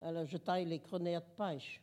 Collectif-Patois (atlas linguistique n°52)